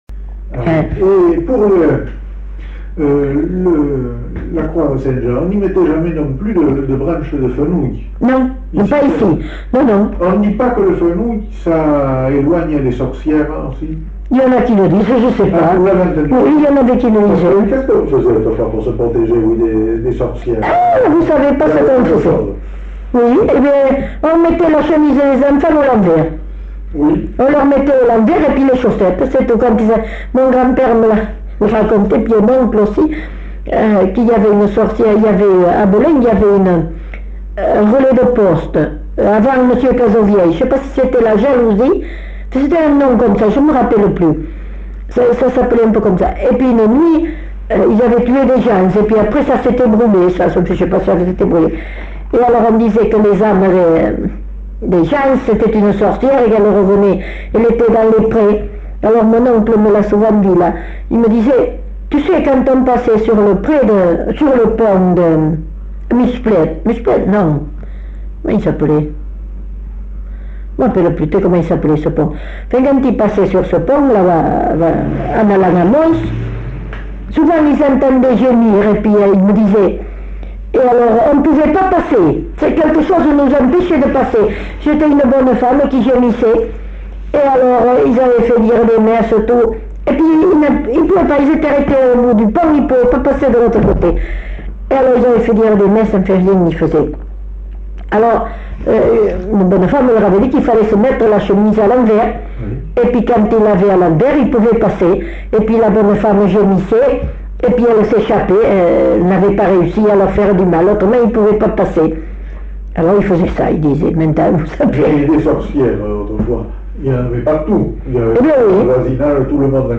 Lieu : Belin-Beliet
Genre : témoignage thématique